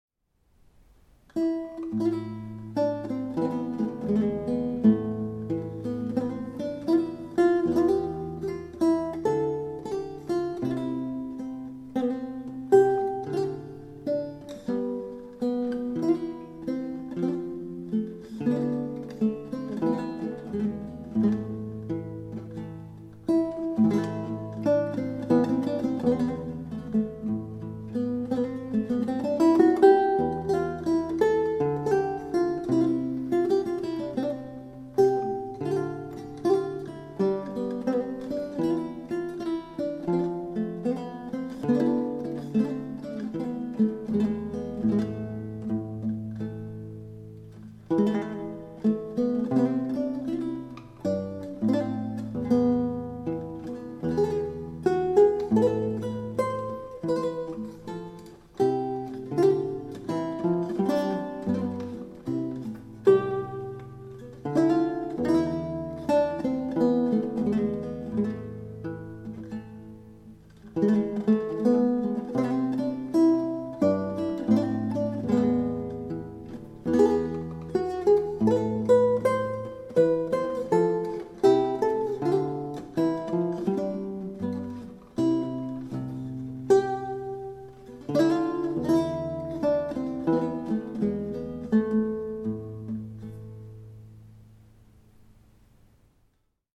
Note on the recordings:  the same seven-course lute (string length 673mm, made in 1982) was used for both the recordings.  The Almande was recorded in July 2008 using all-gut strings and at a lower pitch than the other recording, which all used Nylgut stringing, apart from a Savarez KF string for the 5th course and a new prototype "stretchy" NGE string for the 6th, with the same loaded gut as before for the 7th course.